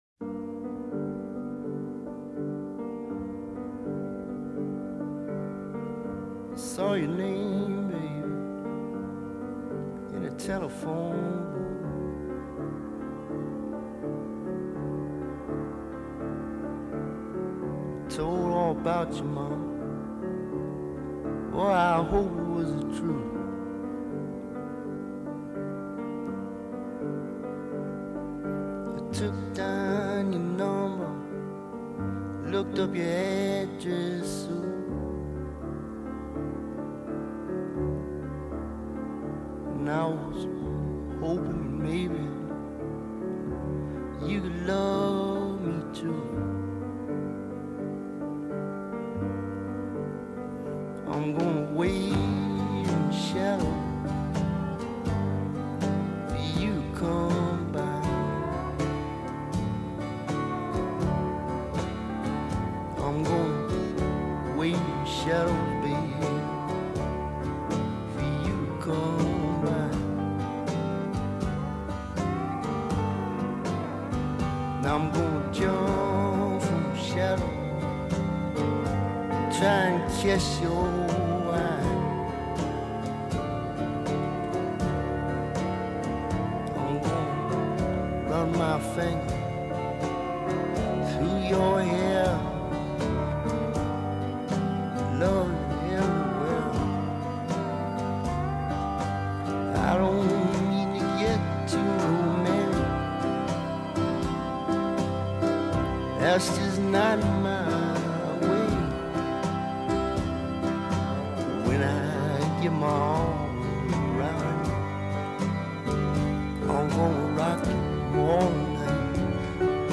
Very haunting.